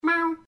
Sound Buttons: Sound Buttons View : Gary Meow
gary_meow-1.mp3